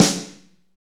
Index of /90_sSampleCDs/Northstar - Drumscapes Roland/DRM_Slow Shuffle/SNR_S_S Snares x